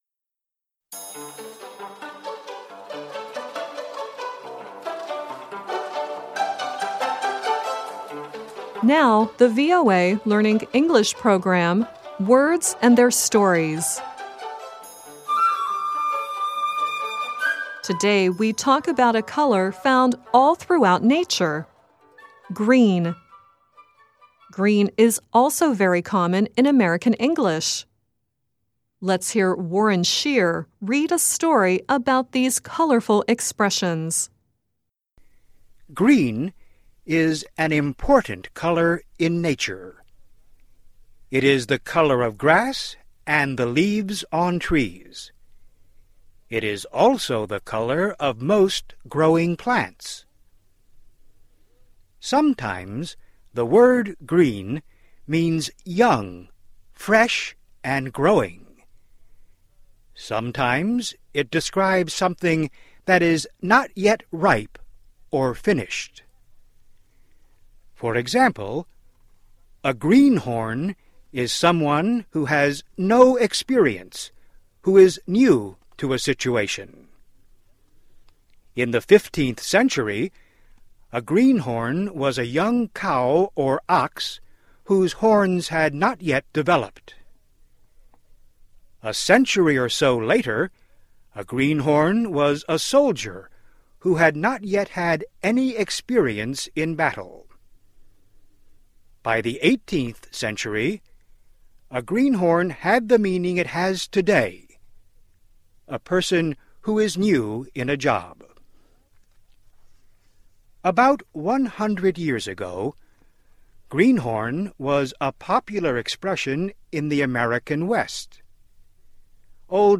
by VOA - Voice of America English News